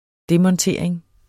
Udtale [ ˈdemʌnˌteɐ̯ˀeŋ ]